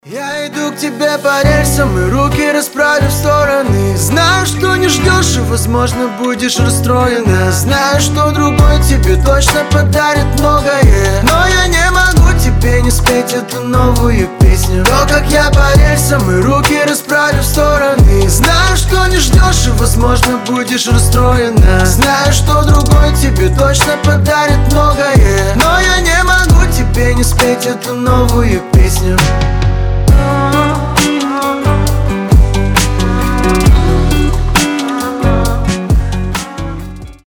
• Качество: 320, Stereo
гитара
лирика